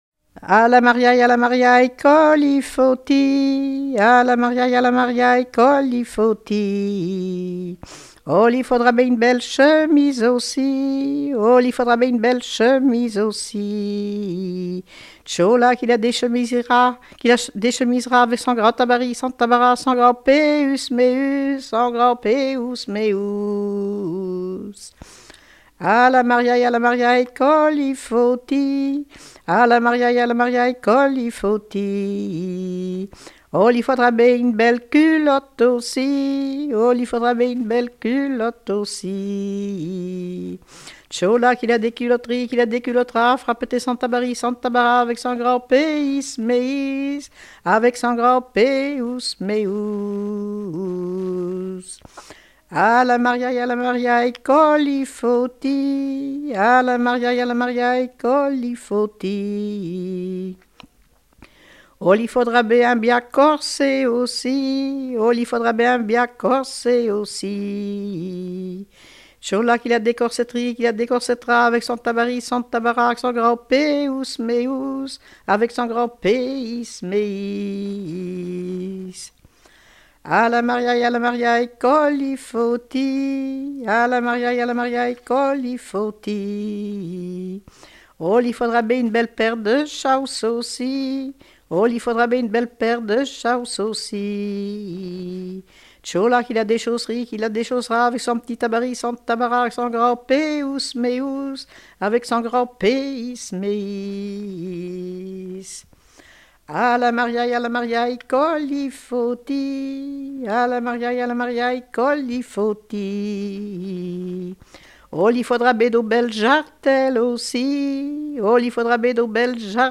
circonstance : fiançaille, noce ;
Genre énumérative
Pièce musicale éditée